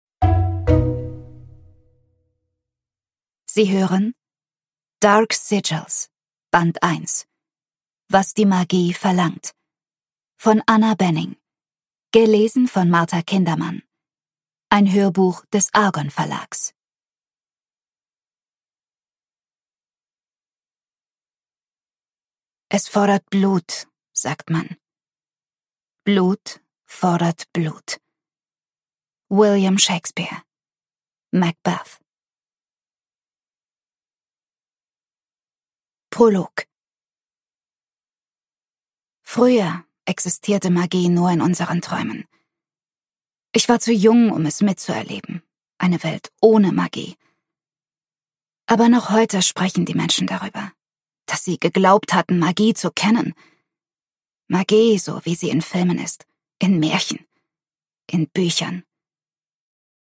Anna Benning: Was die Magie verlangt - Dark Sigils, Band 1 (Ungekürzte Lesung)
Hörbuch-Download Gelesen von